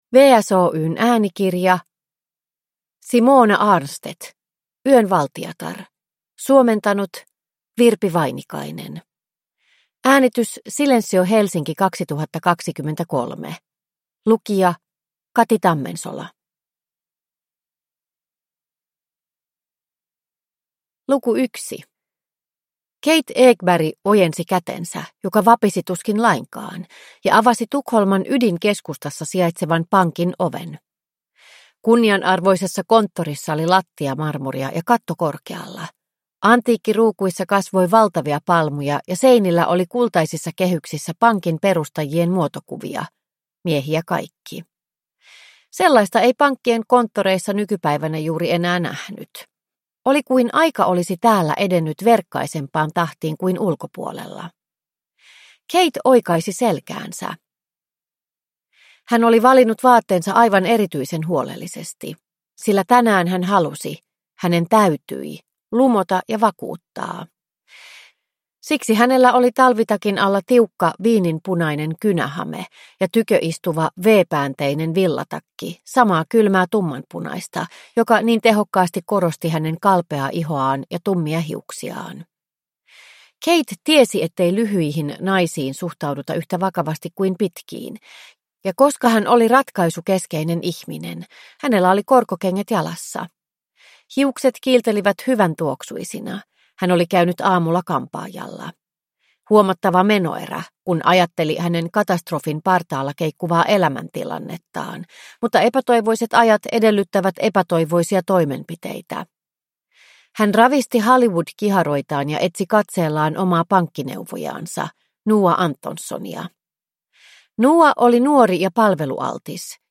Yön valtiatar – Ljudbok